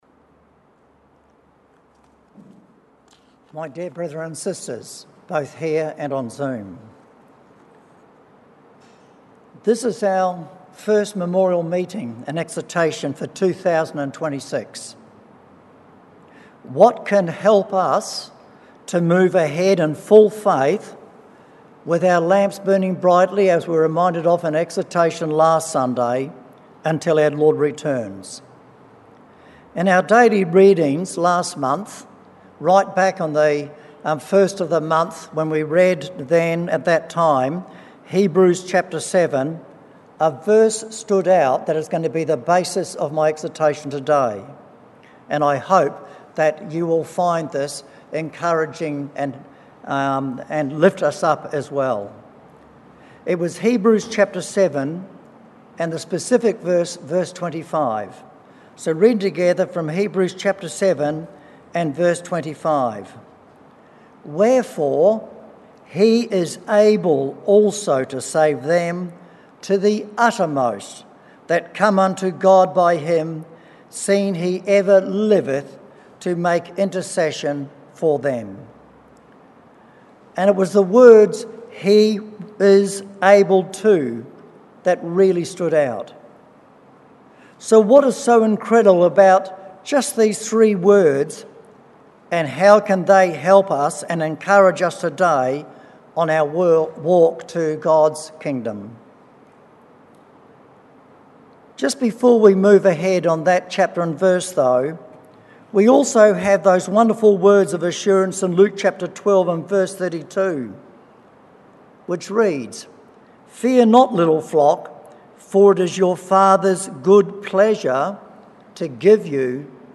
Exhortations